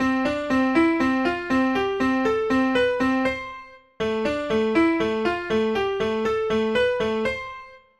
そのため、使う音が同じでも、聞こえ方が変わります。
↓の音源は、「Cを中心にCDEFGABを弾いたもの」と「Aを中心にCDEFGABを弾いたもの」で、同じCDEFGABしか使っていないのに、異なって聞こえるのがわかると思います。
CメジャーとAマイナー